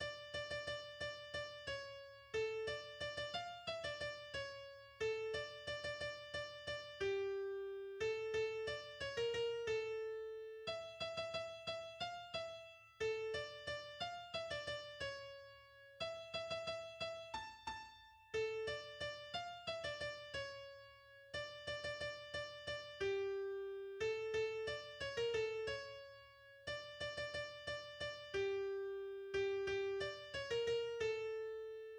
ניגון יהודי-רוסי עתיק (ספר הניגונים #251)
הניגון מופיע בסולם מינורי. לפי גרסת חב"ד בסולם פריגיש ולפי נוסח קרלין בסולם מינור הרמוני עם חריגה בסיום כל קטע לסולם פריגיש.
הסיום על פי נוסח חב"ד[5]: